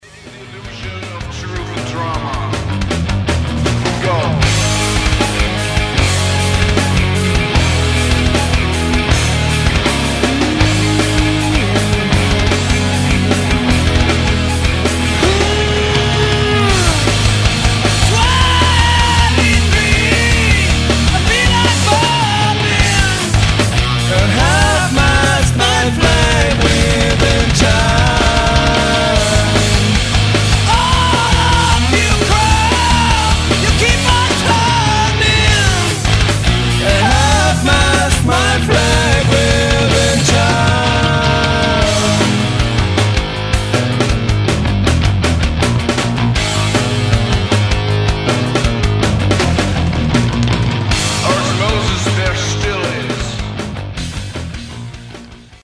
Kerniges Riffing